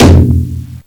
taiko-soft-hitfinish.wav